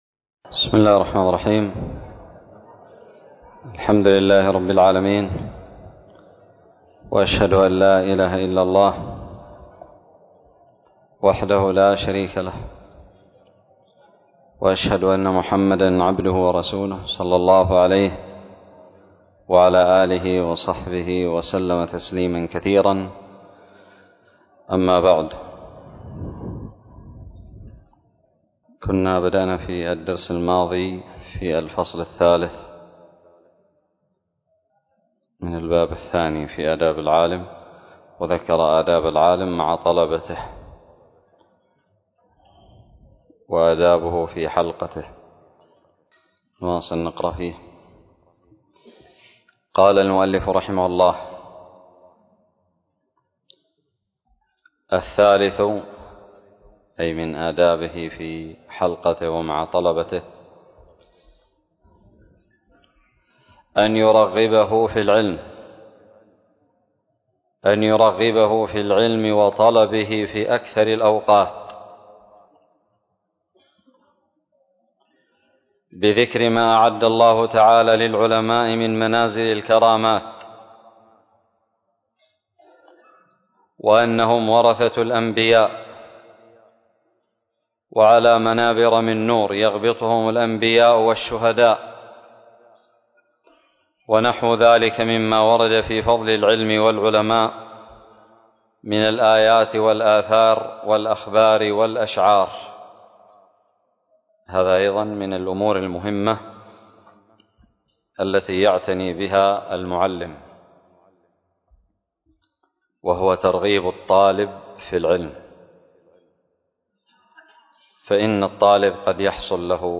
الدرس السادس عشر من شرح كتاب تذكرة السامع والمتكلم 1444هـ
ألقيت بدار الحديث السلفية للعلوم الشرعية بالضالع